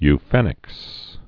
(y-fĕnĭks)